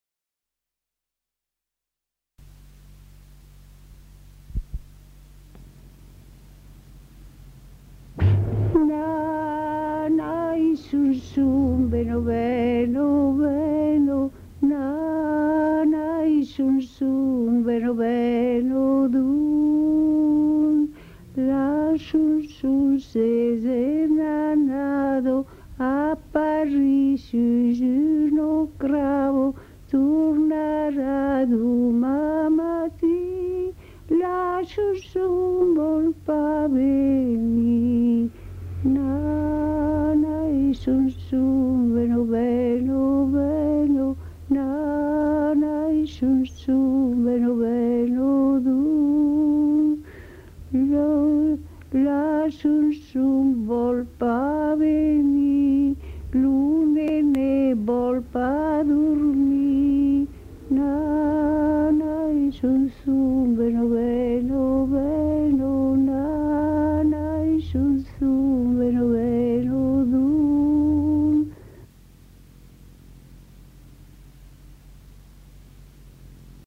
Aire culturelle : Haut-Agenais
Genre : chant
Effectif : 1
Type de voix : voix de femme
Production du son : chanté
Description de l'item : fragment ; 2 c. ; refr.
Classification : som-soms, nénies